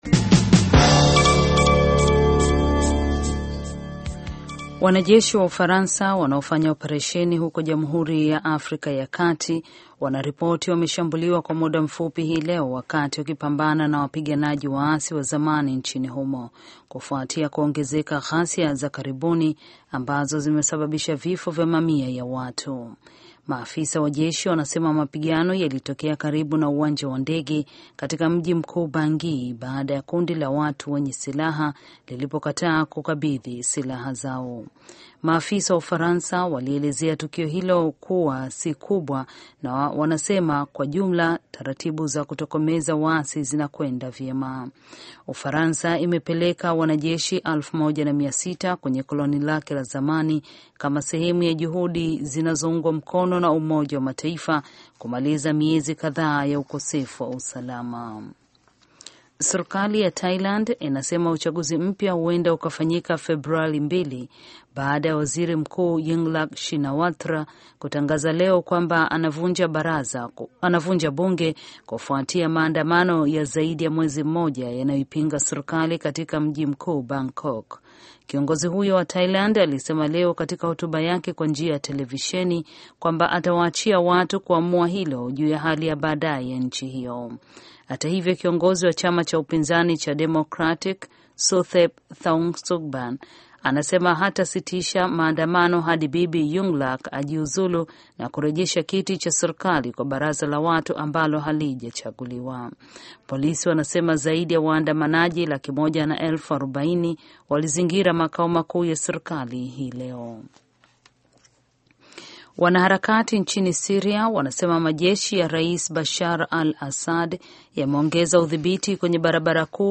Taarifa ya Habari VOA Swahili - 5:40